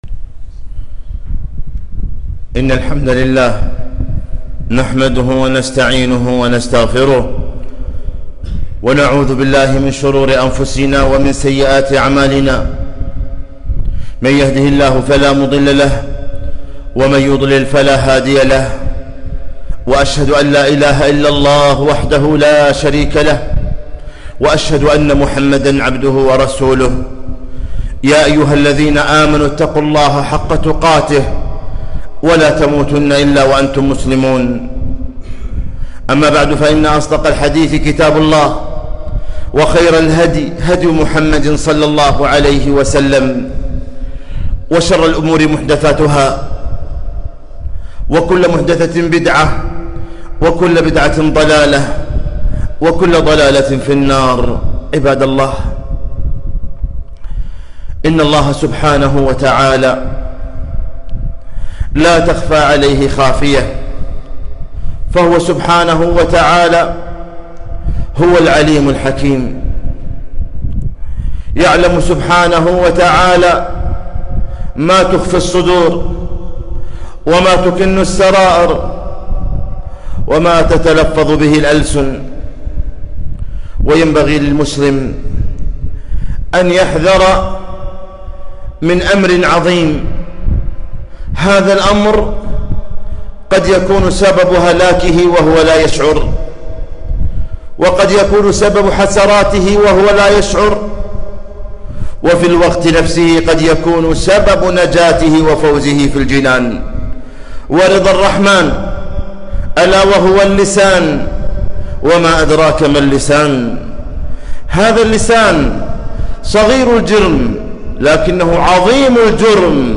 خطبة - احفظ لسانك